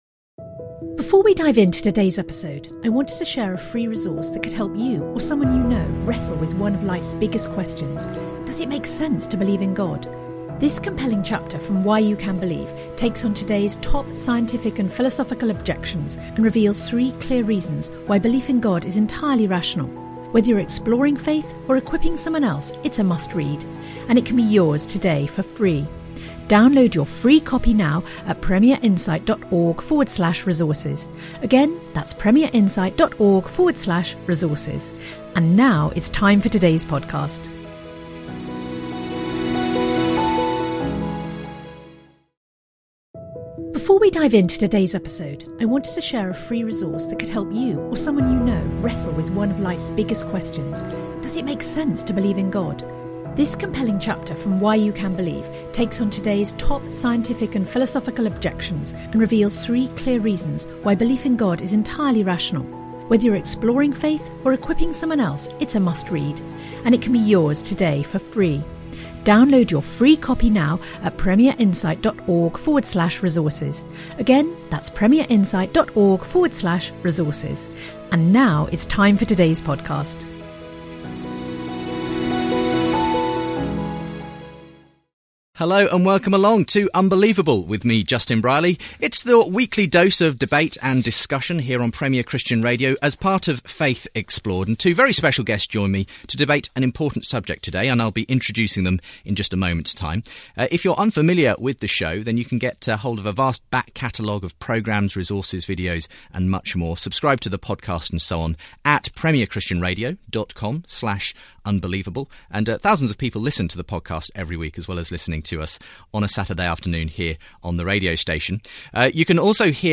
They debate whether the UK should hold on to its Christian heritage.